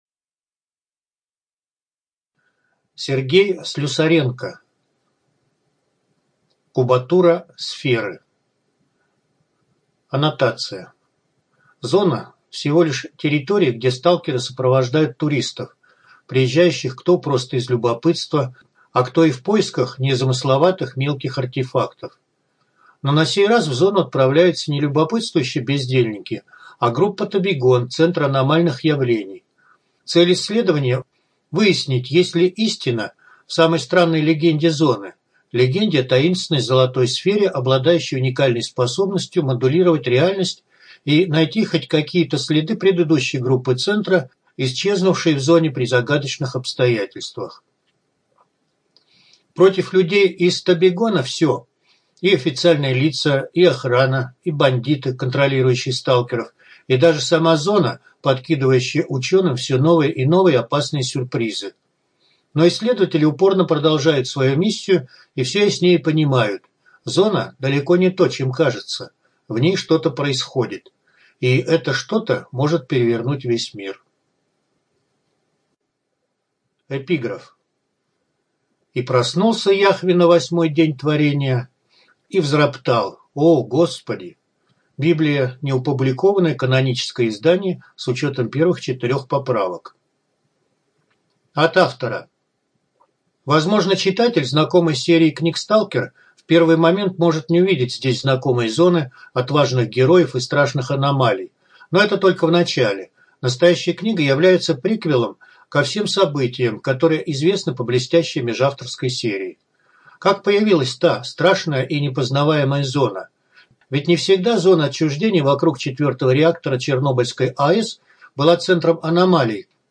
ЖанрФантастика